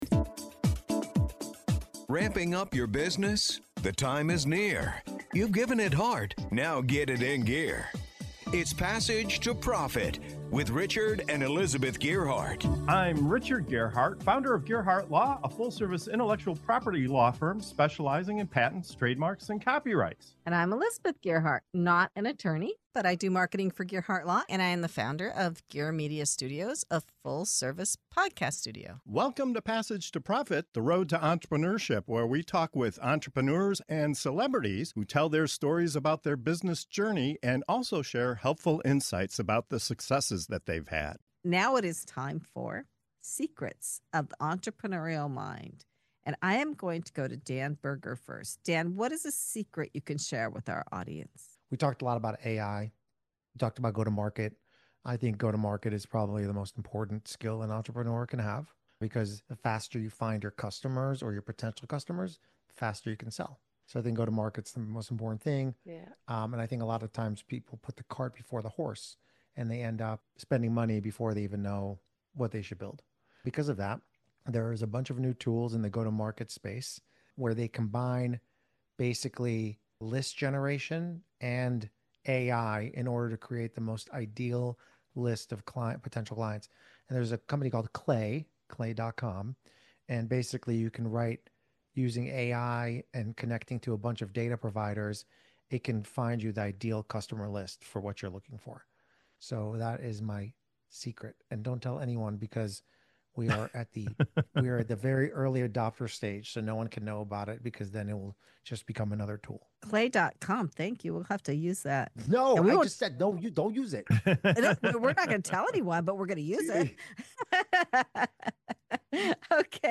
In this segment of "Secrets of the Entrepreneurial Mind" on Passage to Profit Show, our guests share the insider tips, mindset shifts, and under-the-radar tools driving their success. From a game-changing AI platform that finds your ideal customers to the importance of go-to-market strategy, you'll hear practical advice you can act on today.